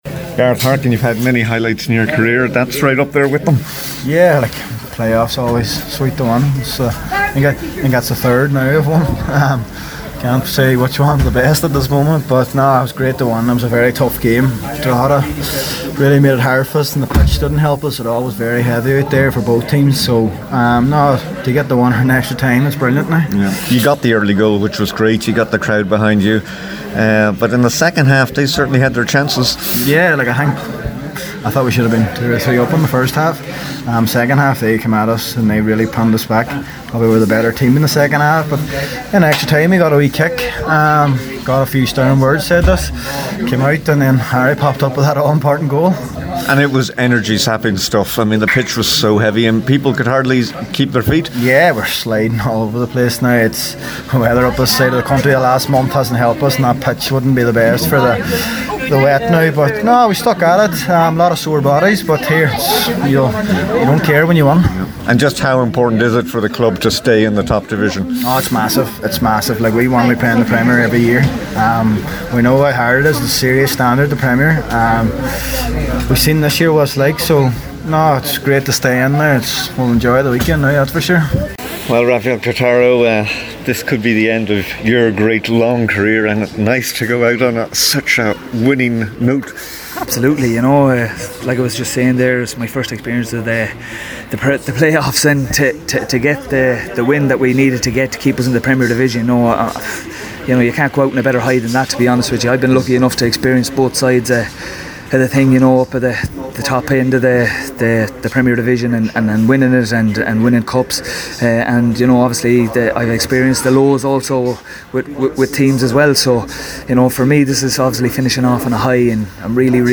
after the final whistle…